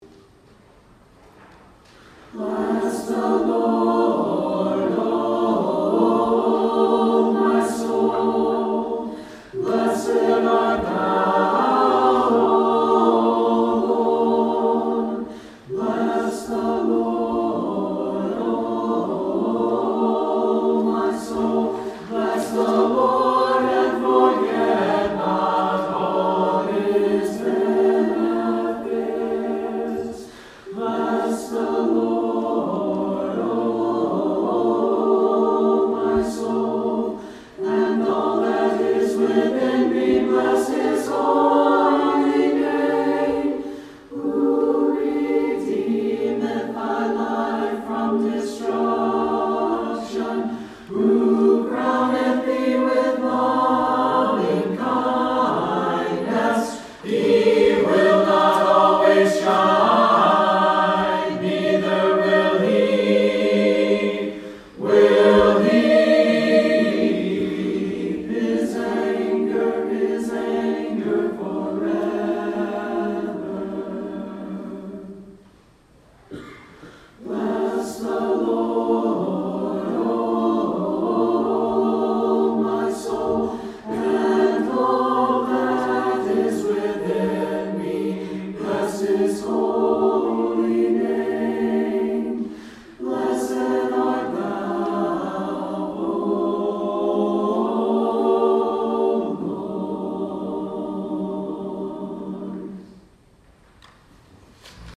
Call to Worship: Trinity Chancel Choir